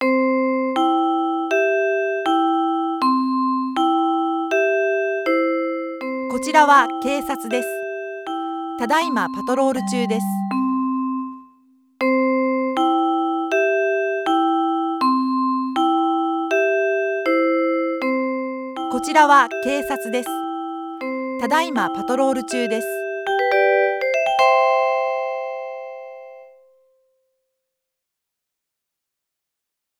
メロディパトロール（愛称「メロパト」）とは、パトカーがパトロール中にメロディを流しながら 走行することです。